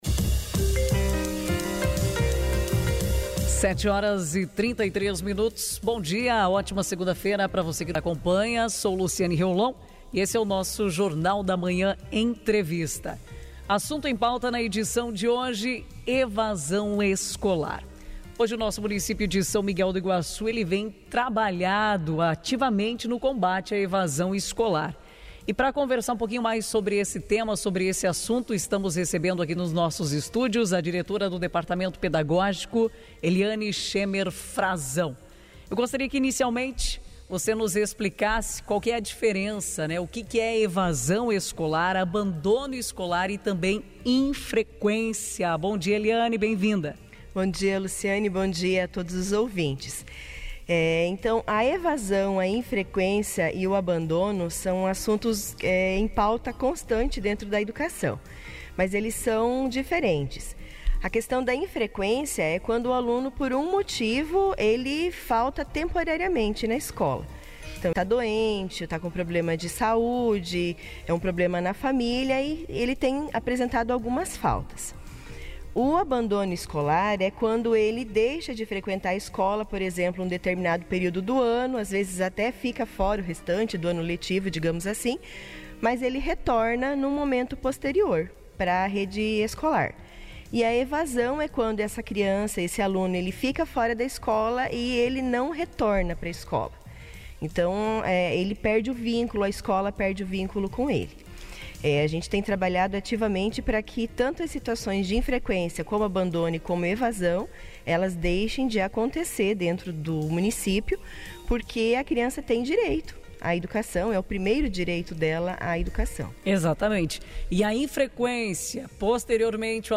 ENTREVISTA-EDUCACAO.mp3